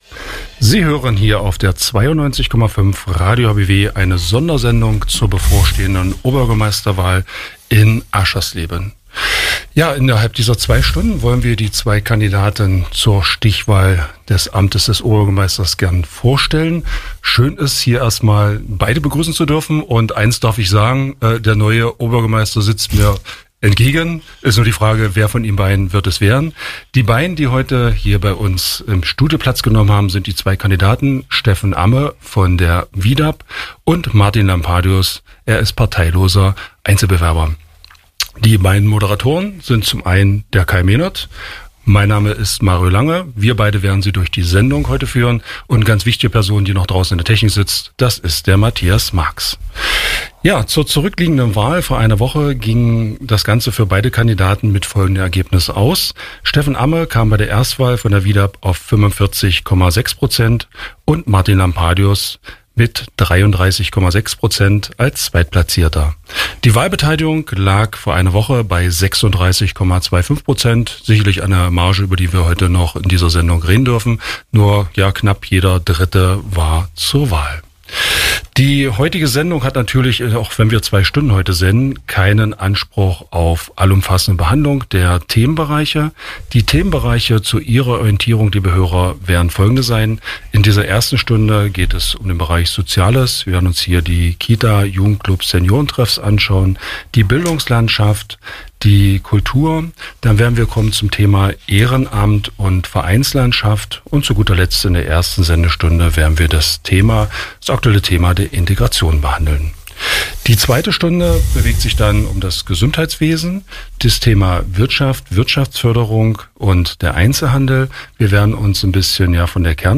Wahl-Duell zur Oberbürgermeister-Stichwahl in Aschersleben
Sie haben im ersten Wahlgang die meisten Stimmen erreicht und standen im Wahl-Duell bei radio hbw noch einmal live Rede und Antwort.